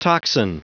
Prononciation du mot toxin en anglais (fichier audio)